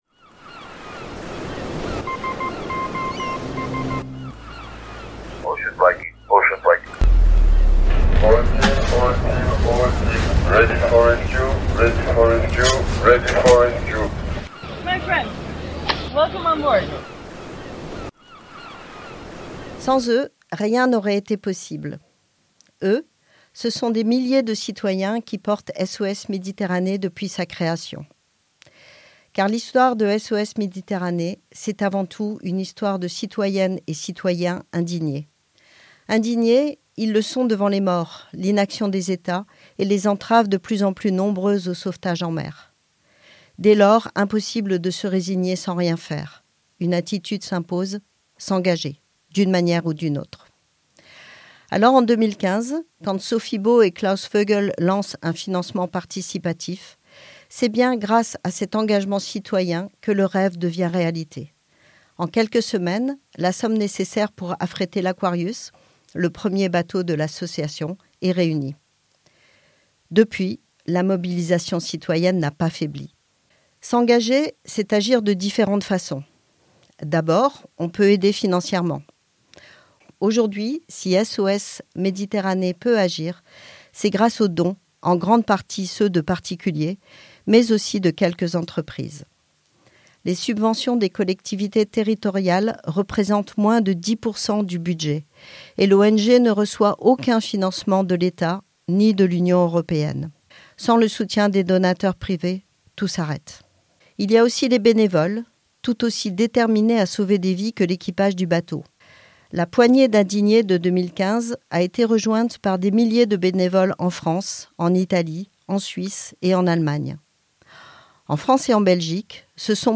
Document sonore : Le message de soutien de Charline Vanhoenacker, marraine de SOS MEDITERRANEE et membre du comité du soutien